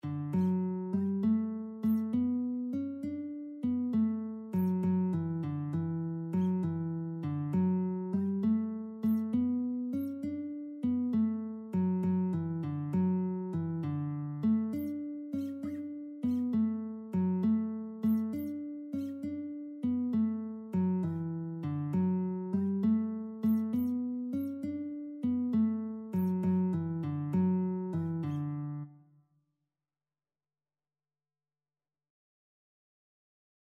Christian
6/8 (View more 6/8 Music)
Classical (View more Classical Lead Sheets Music)